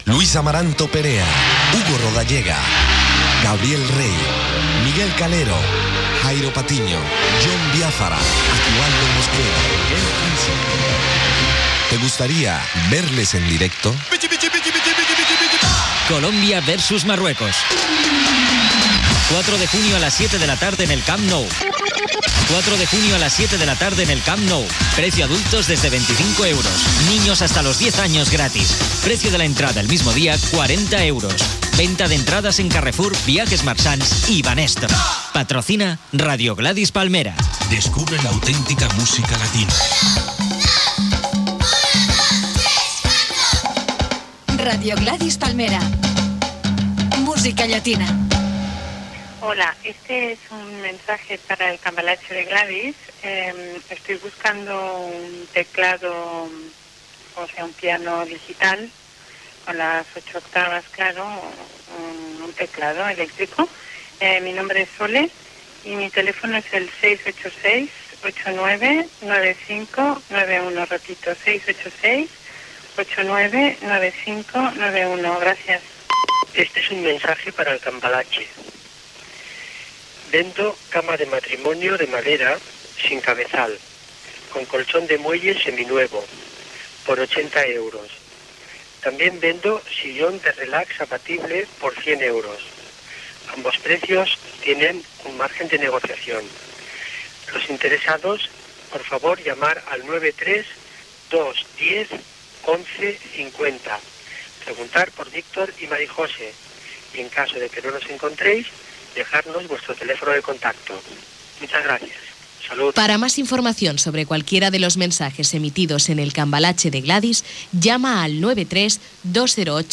Publicitat, indicatiu del programa, missatges de l'audiència de compra venda, publicitat, indicatiu de la ràdio en francès, tema musical, missatges de l'audiència de compra venda, tema musical, publicitat, indicatiu i tema musical
Entreteniment
FM